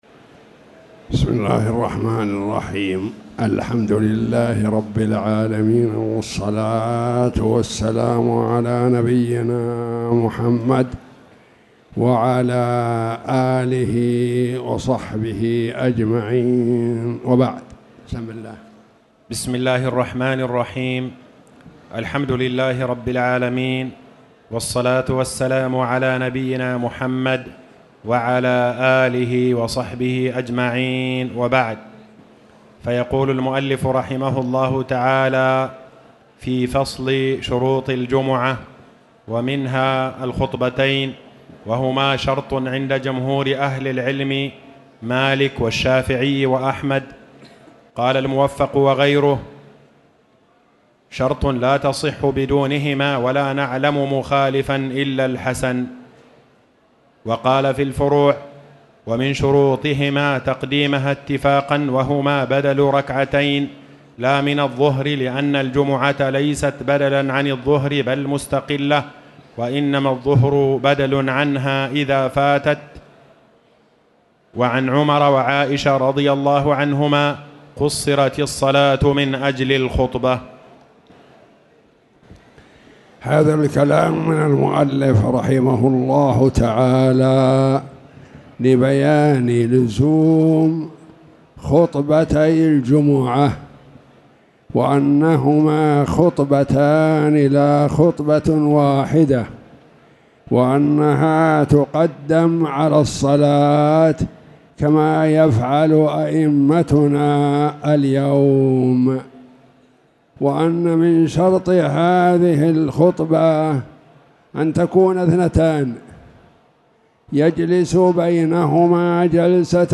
تاريخ النشر ١٦ ربيع الثاني ١٤٣٨ هـ المكان: المسجد الحرام الشيخ